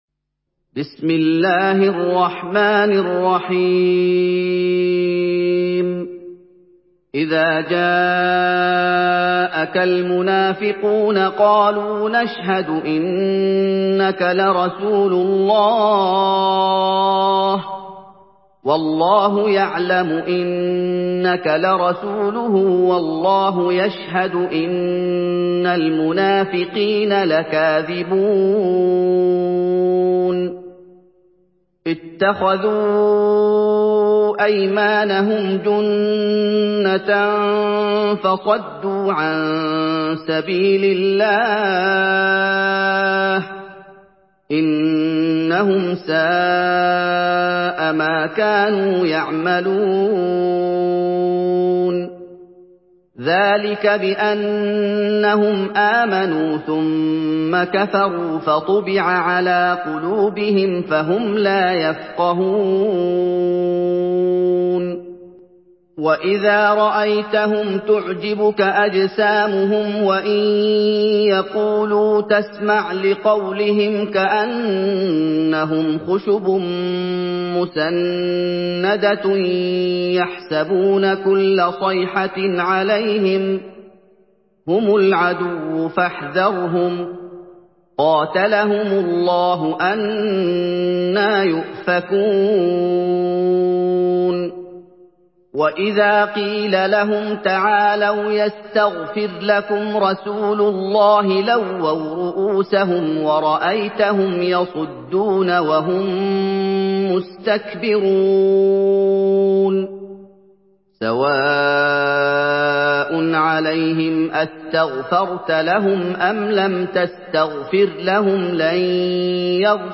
Surah আল-মুনাফিক্বূন MP3 in the Voice of Muhammad Ayoub in Hafs Narration
Surah আল-মুনাফিক্বূন MP3 by Muhammad Ayoub in Hafs An Asim narration.
Murattal Hafs An Asim